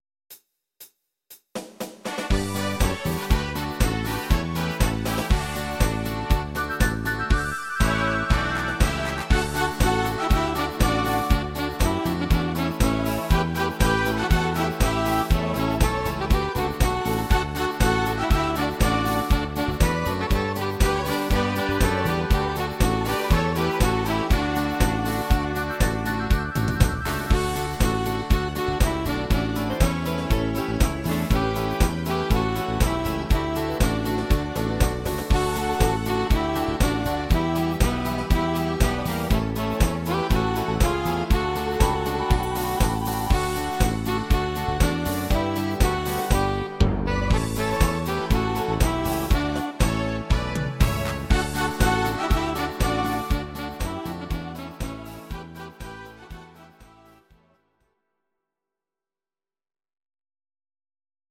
Audio Recordings based on Midi-files
German, Volkstï¿½mlich